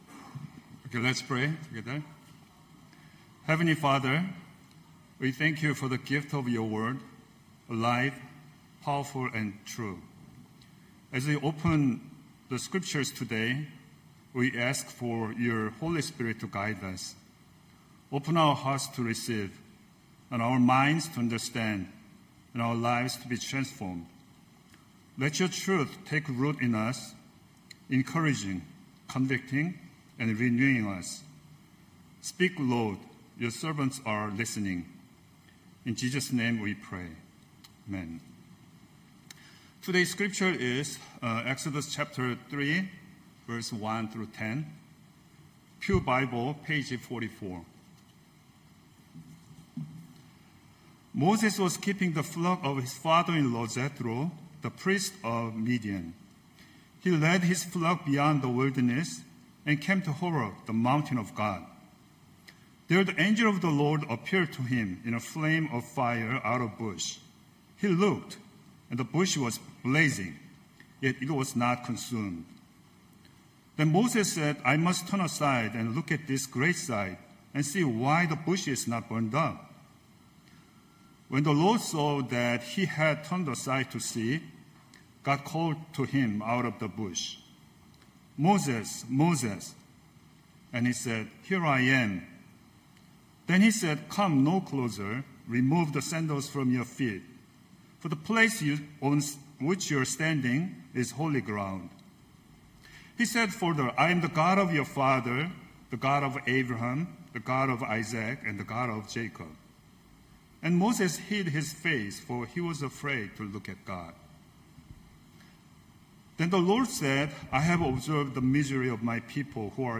Knox Pasadena Sermons Just Another Burning Bush Aug 10 2025 | 00:21:01 1x 00:00 / 00:21:01 Subscribe Share Spotify RSS Feed Share Link Embed